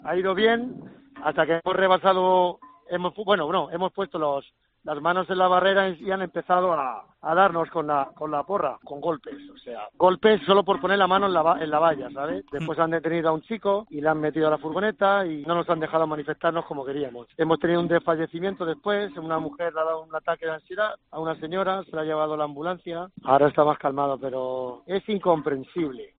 Declaraciones ganadero